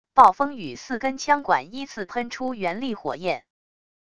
暴风雨四根枪管依次喷出原力火焰wav音频